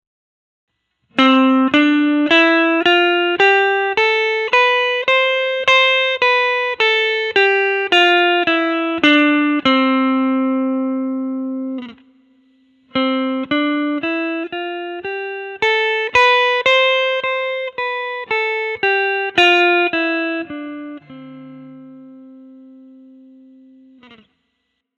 ドレミファソラシド
実際に、ドレミファソラシドを強弱を変えて弾き比べてみました。
2つ目の方が、演奏に「うねり」や「感情」が乗っているように聞こえるはずです。
同じフレーズでも、右手のタッチの強弱（ダイナミクス）をつけるだけで、ギターはここまで「歌う」ようになります。